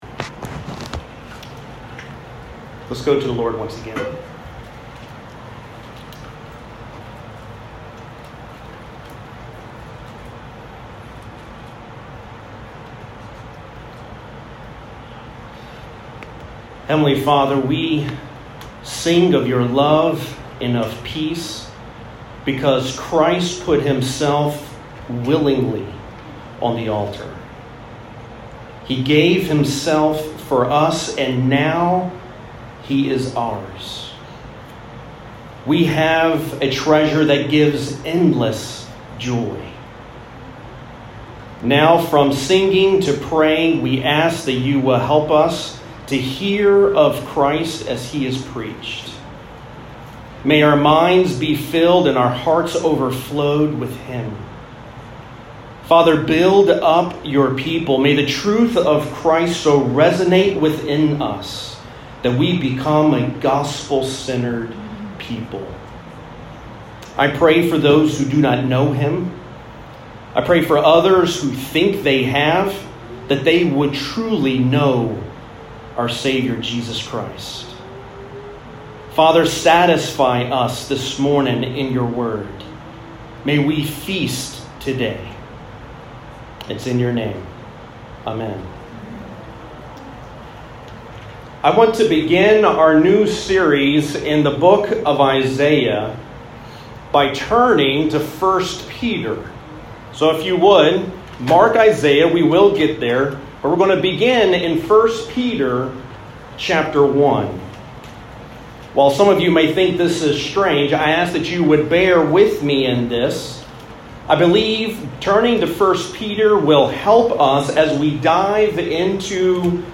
Sermon Outline: Introduction of the Book of Isaiah Overview of Isaiah The Gospel in Isaiah